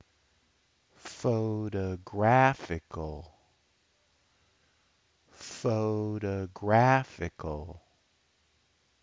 Pho-to-GRAPH-i-cal
[secondary stress] + [unstressed] + [primary stress] + [unstressed] + [unstressed]